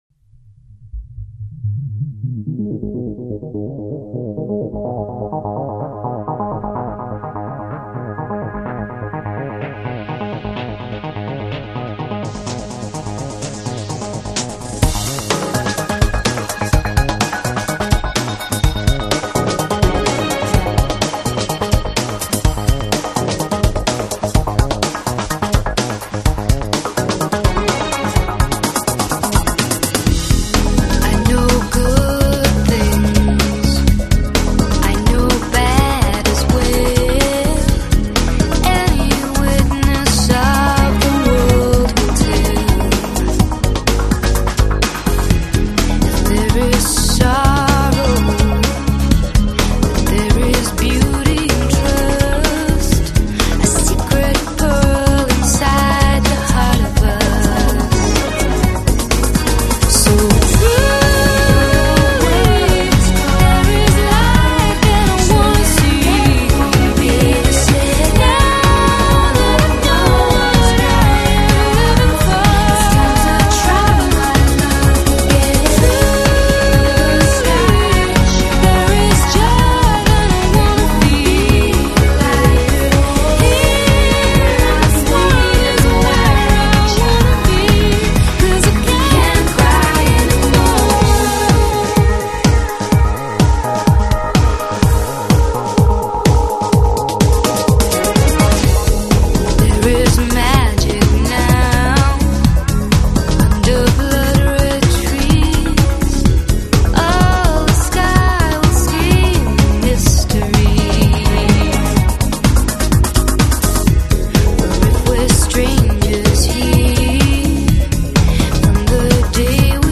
这张专辑用了大量与众不同的女声